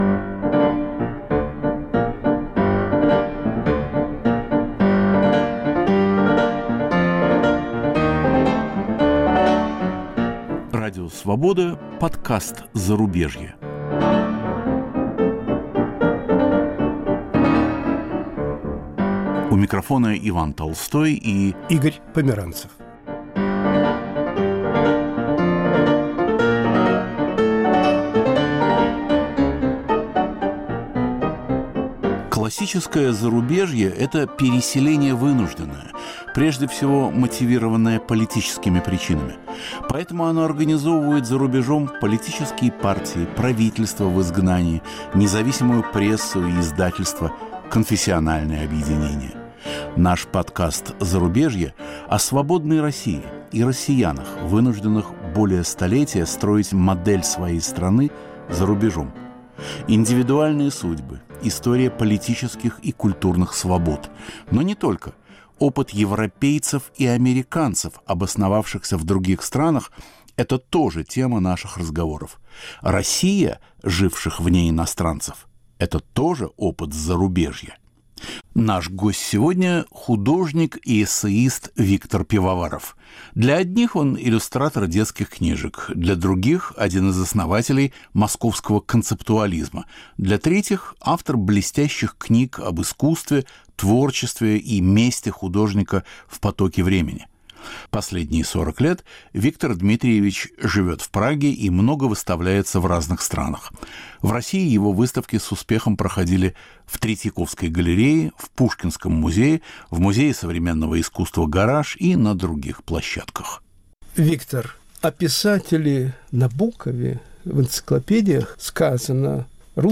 Интервью с Виктором Пивоваровым